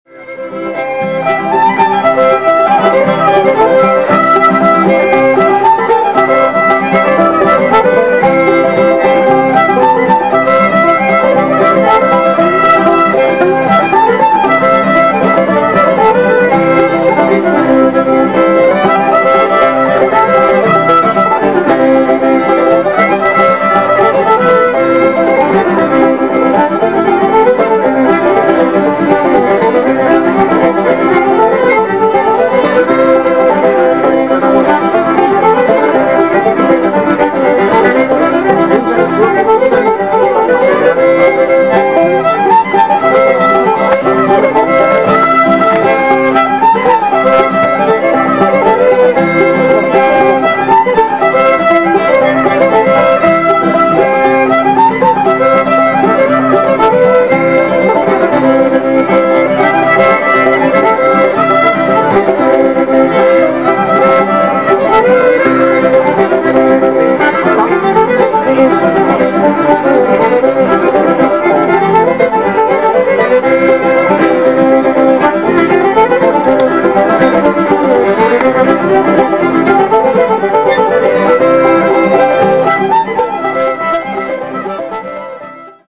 ** Old-Time JAM **
* 2004 TAKARAZUKA BLUEGRASS FEST.
Fiddle
Banjo
Guitar